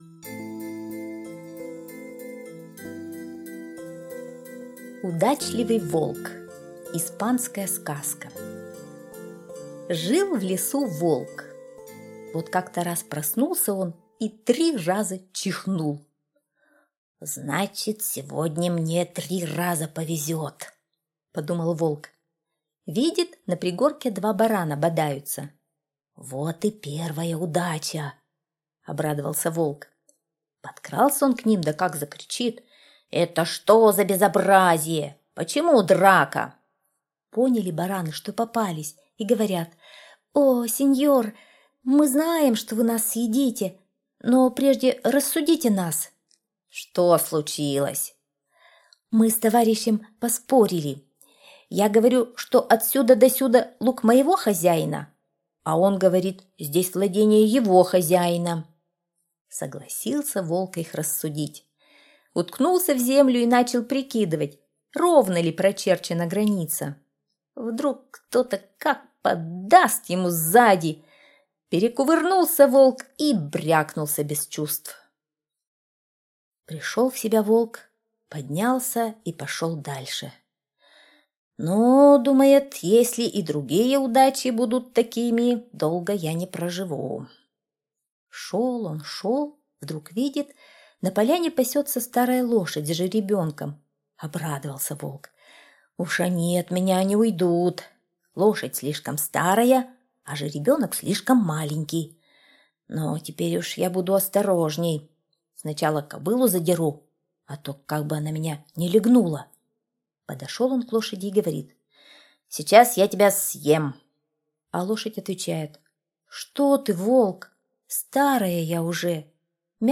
Удачливый волк - испанская аудиосказка - слушать онлайн